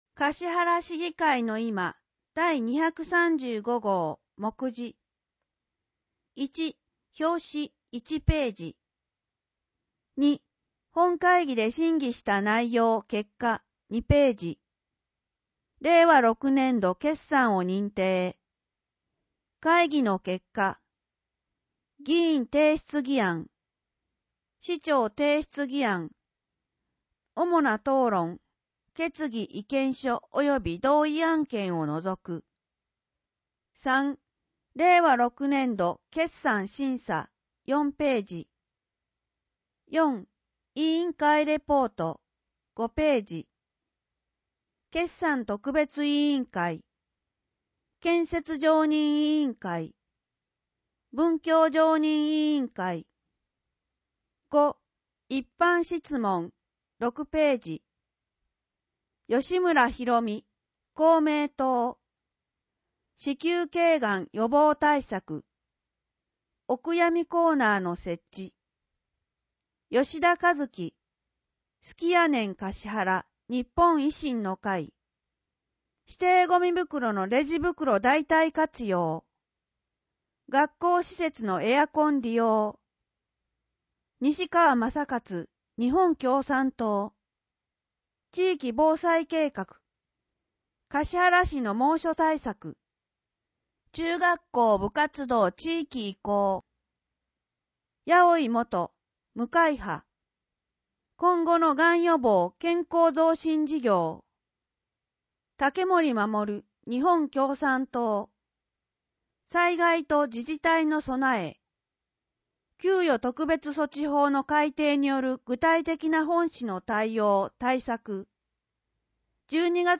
音訳データ
かしはら市議会のいま第235号 (PDFファイル: 4.3MB) 音訳データ かしはら市議会のいま第235号の音訳をお聞きいただけます。 音訳データは、音訳グループ「声のしおり」の皆さんが音訳されたものを使用しています。